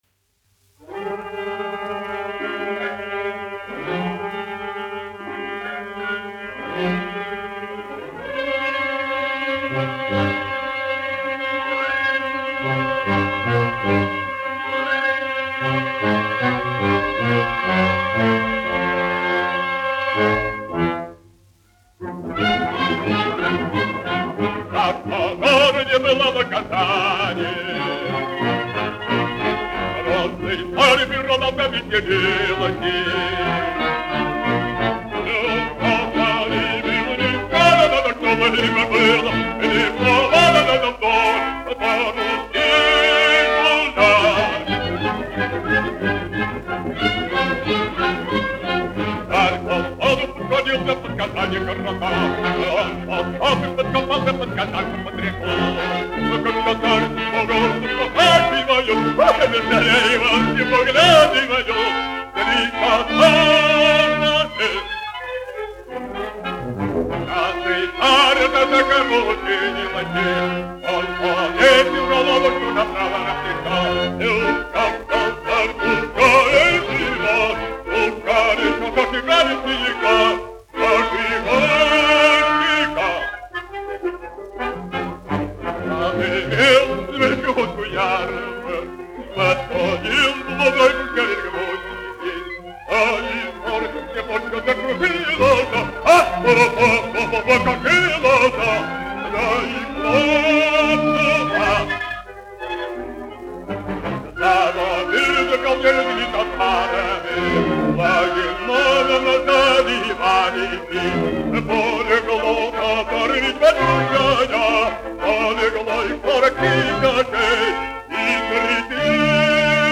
Шаляпин, Федор Иванович, 1873-1938, dziedātājs
1 skpl. : analogs, 78 apgr/min, mono ; 25 cm
Operas--Fragmenti
Skaņuplate
Latvijas vēsturiskie šellaka skaņuplašu ieraksti (Kolekcija)